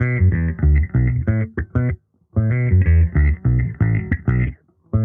Index of /musicradar/sampled-funk-soul-samples/95bpm/Bass
SSF_JBassProc1_95B.wav